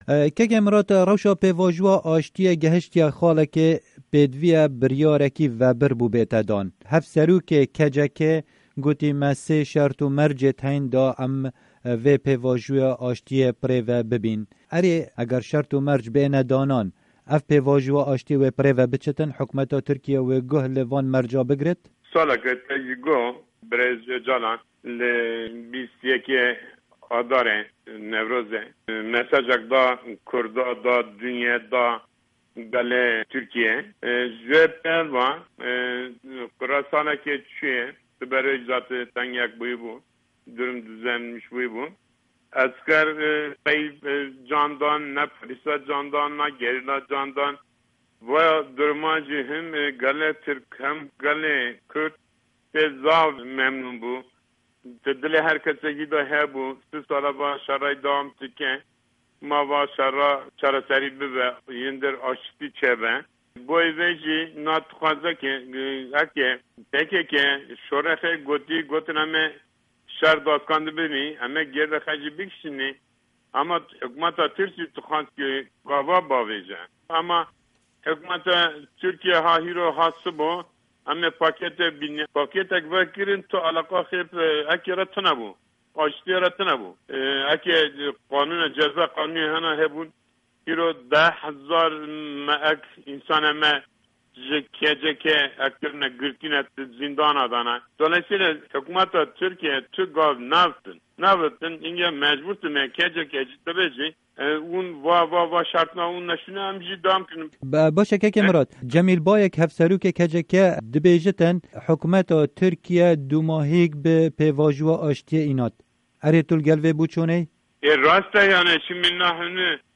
Hevpeyvîn bi Murat Bozlak re